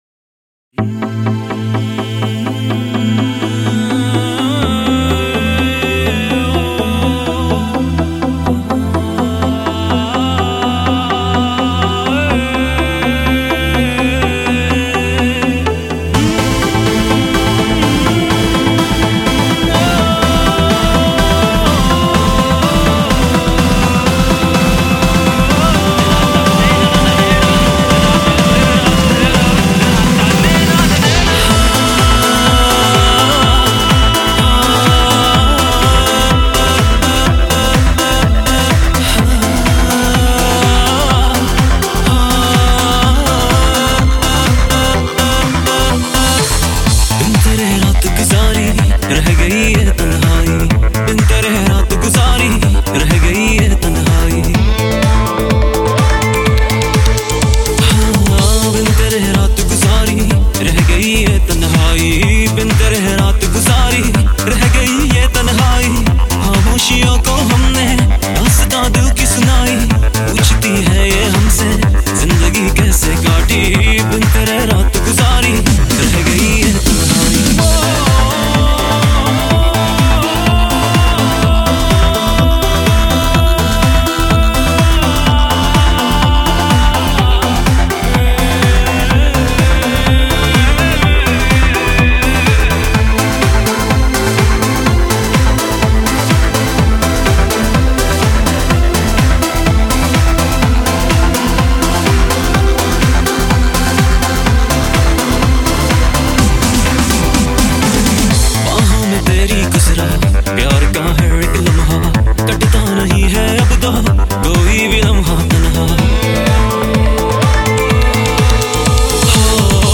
Latest DJ Mixes